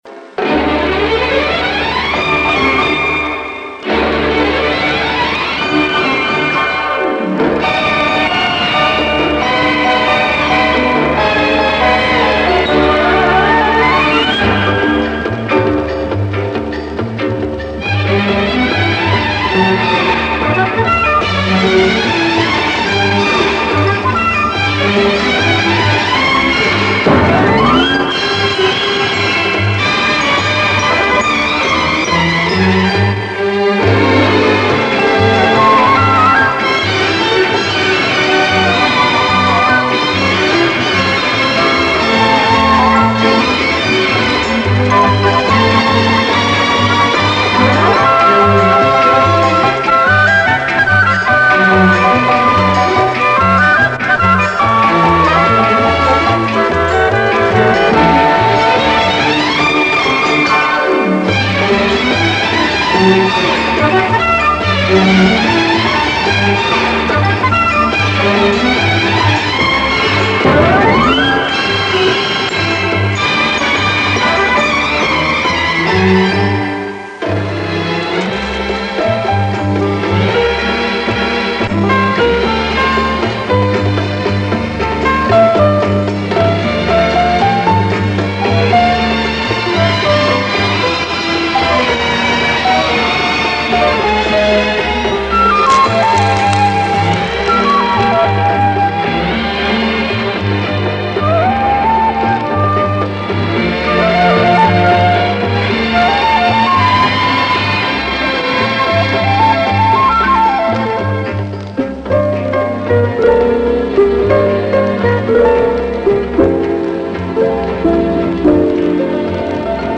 выкладываю записи с плёнок 70-х годов